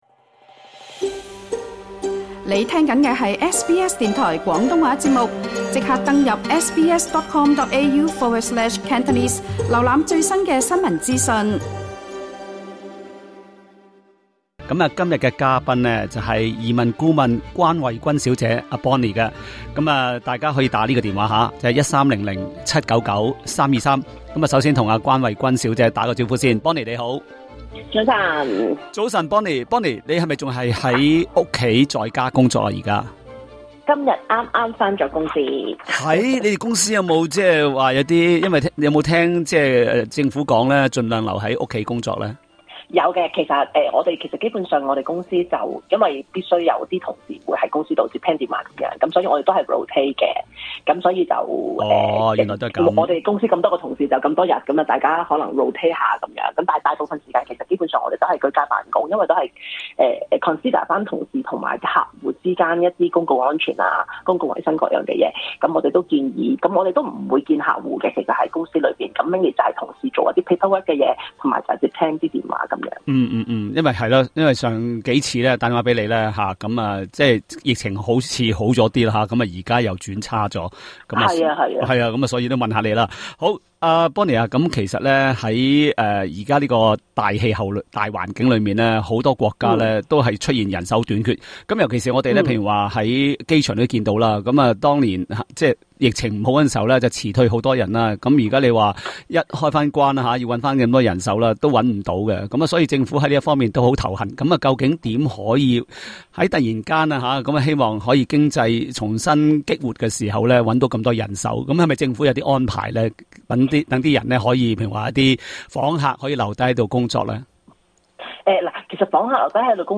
在这节《专家话你知》里，详细解释这个签证的要求，之后并解答听衆的提问，当中有两人查询由中国发出的「无犯罪记录公证」已够期，但签证还未获批，究竟他们是否需要再申请一个新的公证？